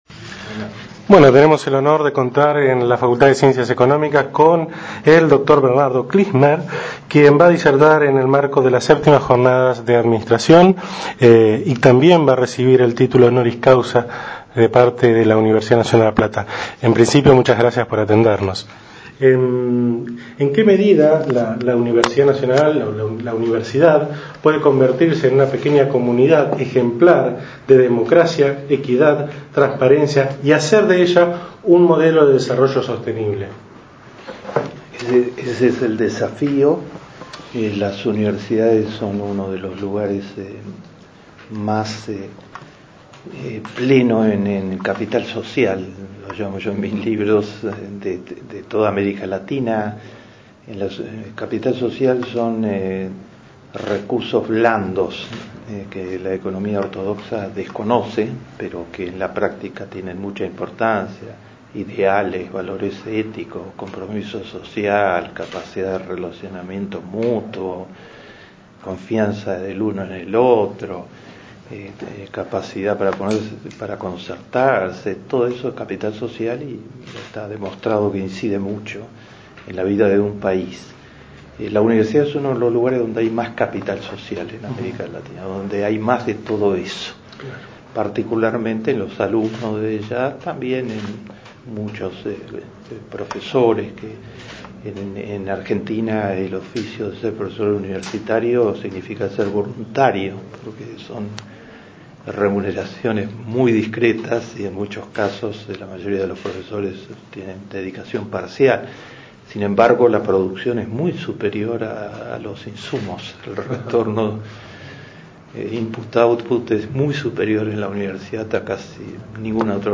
El profesor Bernardo Kliksberg, quien el martes 13 de octubre recibió el título Doctor Honoris Causa de la Universidad Nacional de La Plata, fue entrevistado